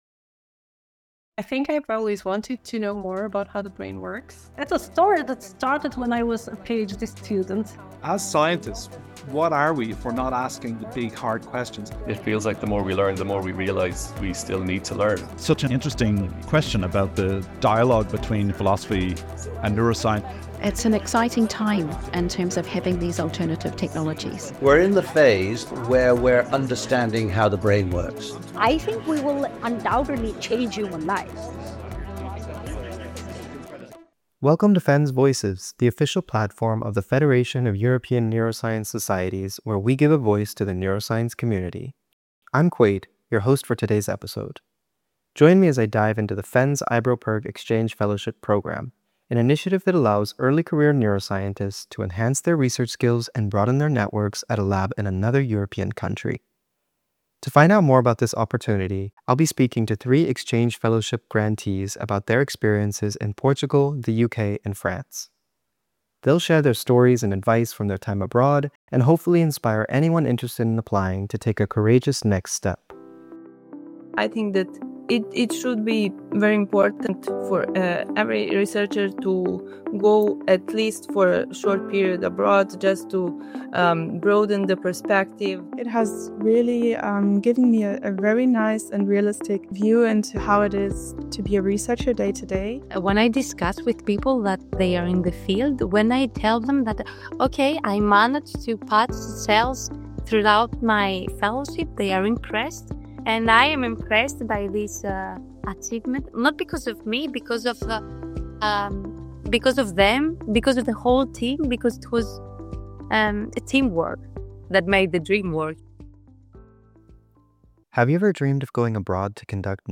To find out more about the programme, we spoke to three Exchange Fellowship Grant Awardees in our latest podcast episode for FENS Voices . They told us about their expectations going into the exchange, shared some of the highlights from their time abroad and tips for future applicants, and explained how the programmed helped them launch a career in brain research.